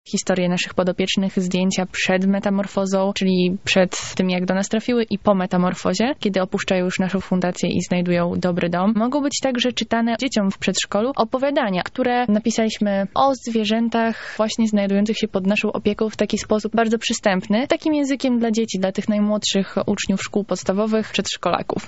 wolontariuszka akcji